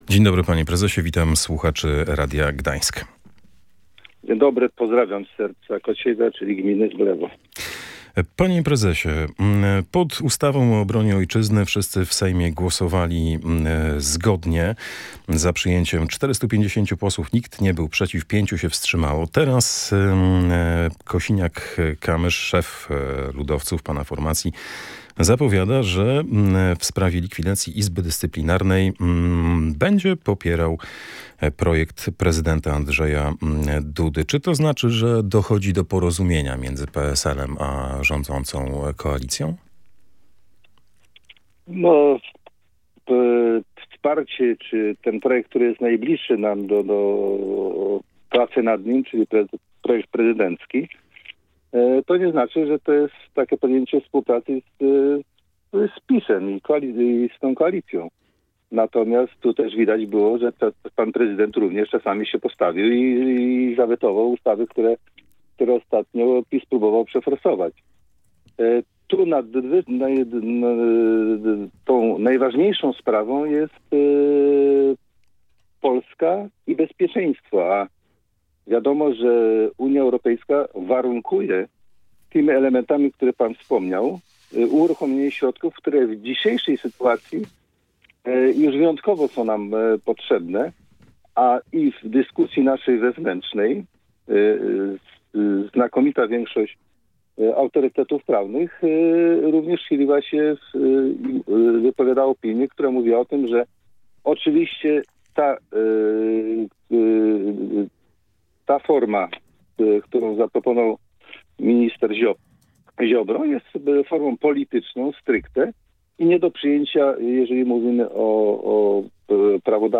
Prowadzący audycję zacytował także Władysława Kosiniaka-Kamysza, który w 2018 roku przekonywał o konieczności likwidacji Wojsk Obrony Terytorialnej.